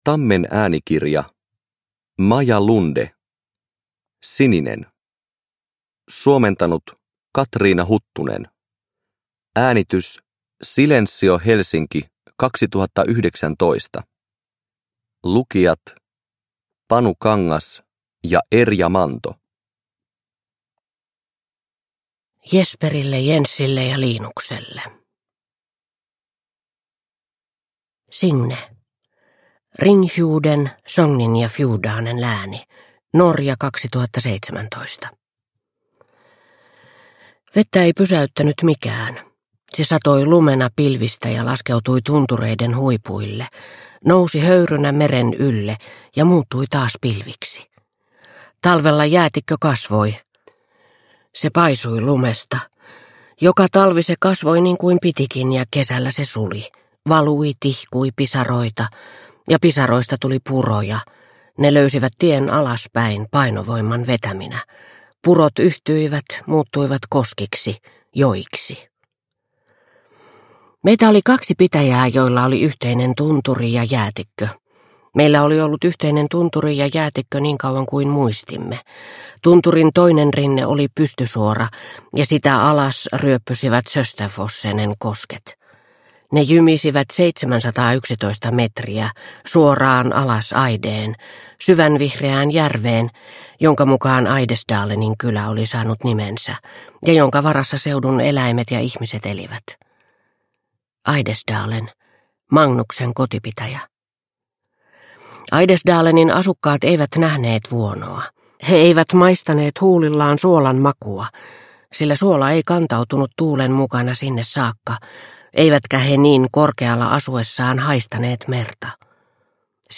Sininen – Ljudbok – Laddas ner